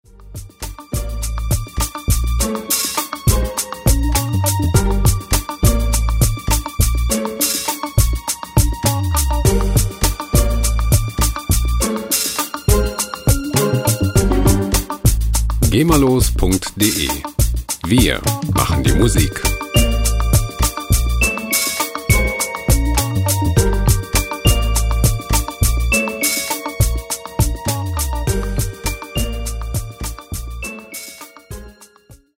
• Cinemtaic Jazz-Hop